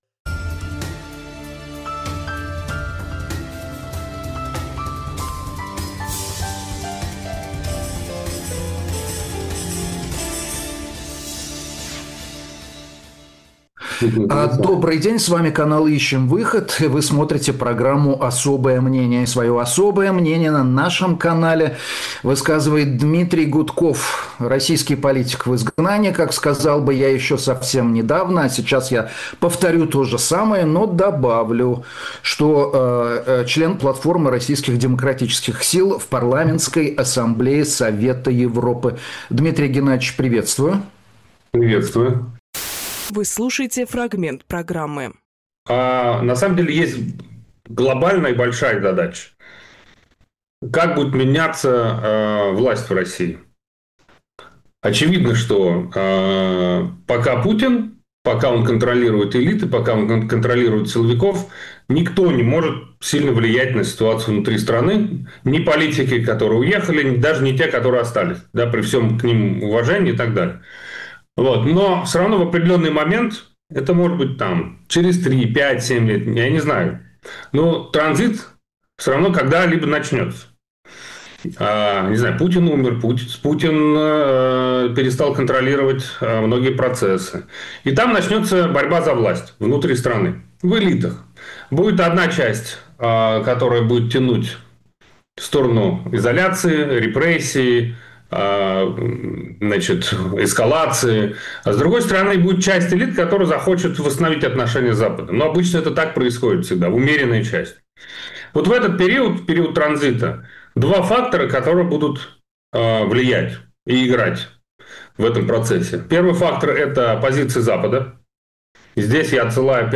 Дмитрий Гудковполитик
Дмитрий Губинжурналист
Фрагмент эфира от 02.02.26